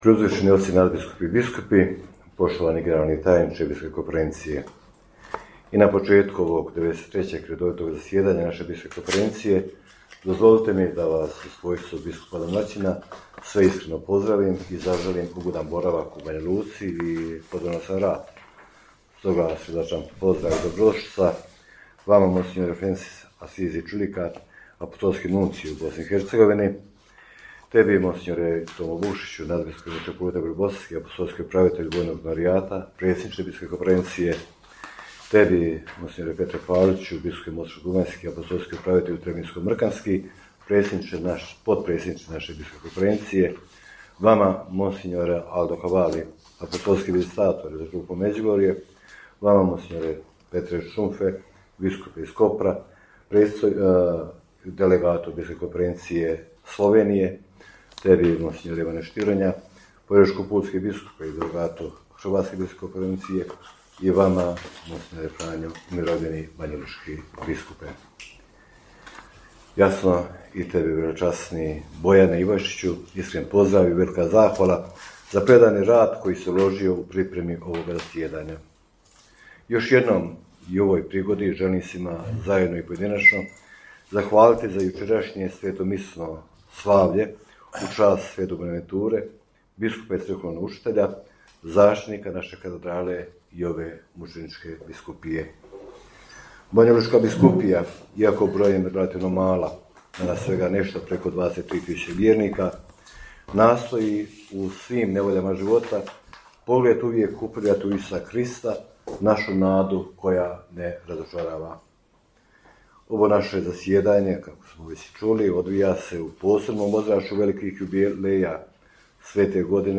Na početku 93. redovitoga zasjedanja Biskupske konferencije BiH, koje je počelo u srijedu 16. srpnja u Banjoj Luci, pozdravni govor je uputio domaćin susreta, banjolučki biskup mons. Željko Majić.